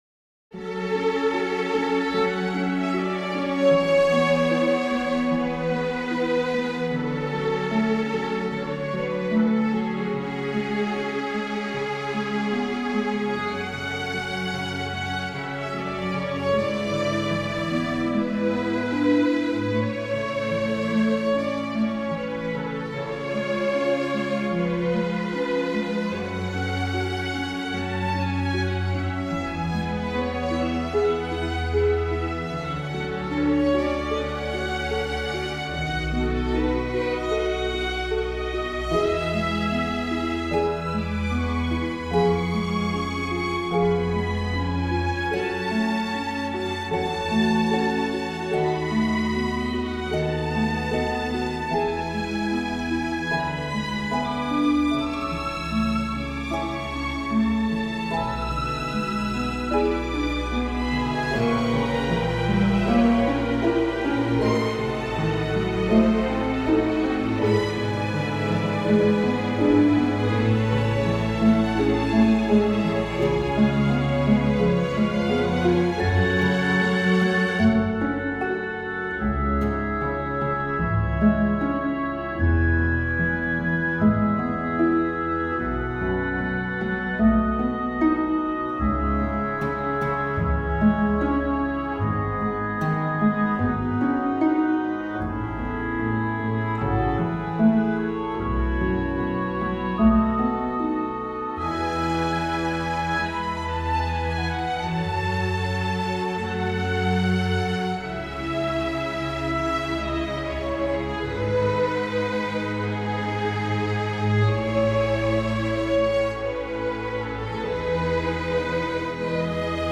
Van de stukken voor orkest is er met behulp van StaffPad een synthetische "weergave" worden gemaakt.
Op.54 No.7 Sinfonietta Symfonieorkest september 2025 Symfonieorkest, harp, houtblazers, trombones en slagwerk